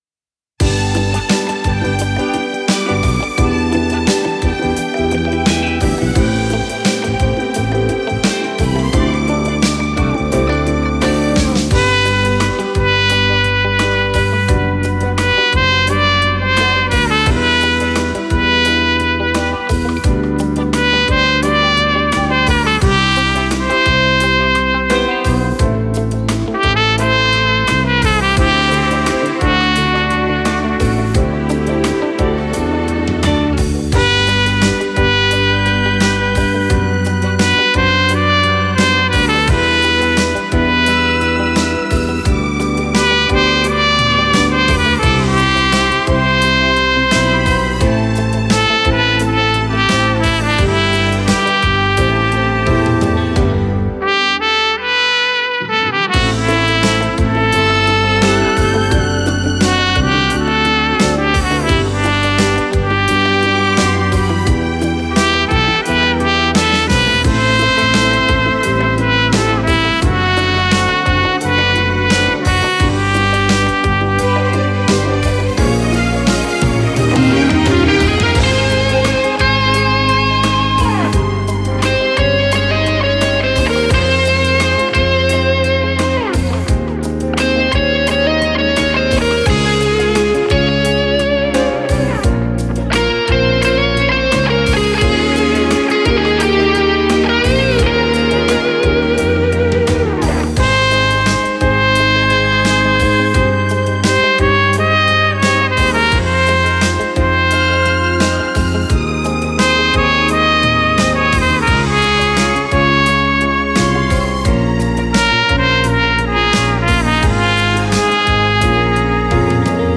9 Ballabili per tromba